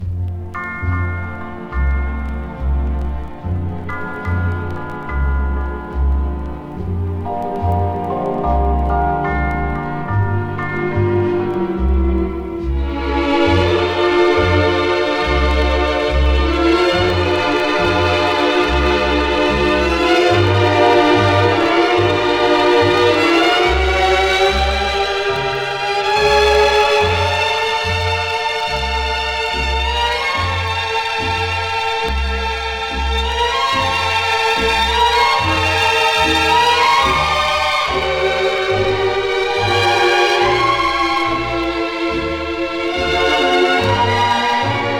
Jazz, Pop, Easy Listening　UK　12inchレコード　33rpm　Mono/Stereo